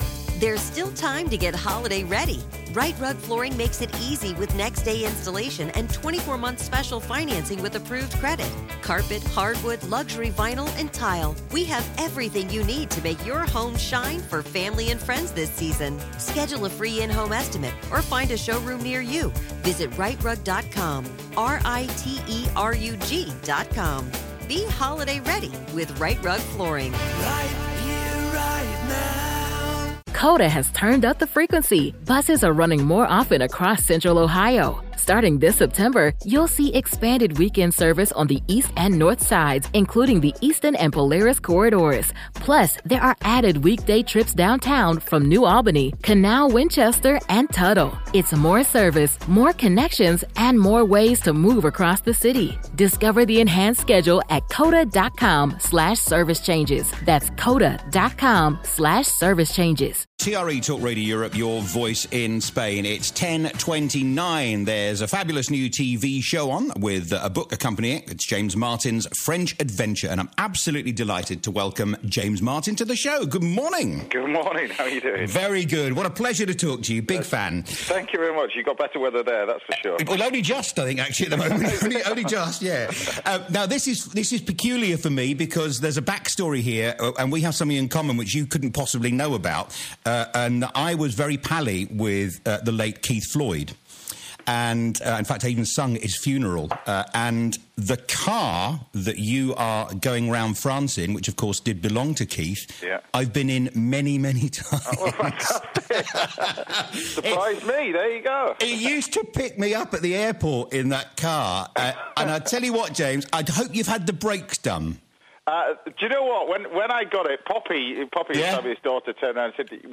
interviews Chef James Martin about his life , and in particular his respect for the late great Keith Floyd. It concludes with a 'bonus track'